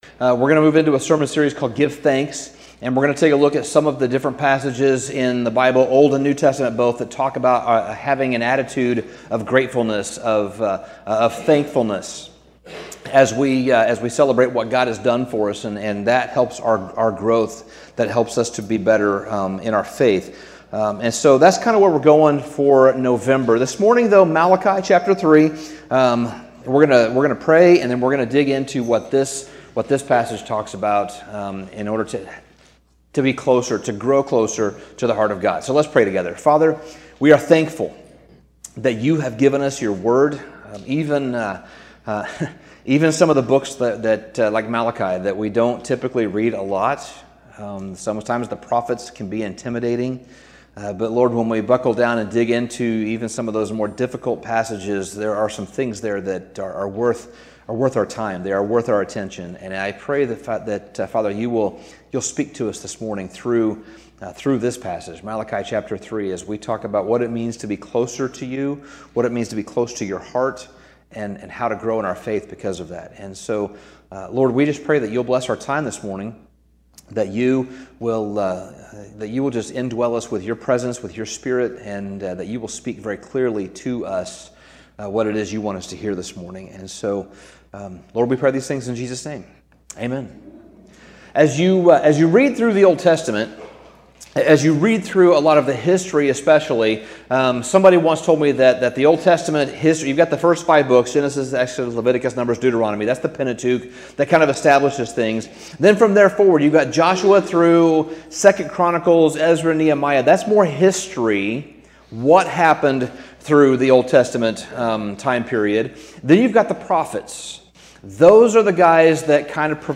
Sermon Summary In Malachi, at the end of the Old Testament, we see a brief description of what God's people were called to, but not living up to.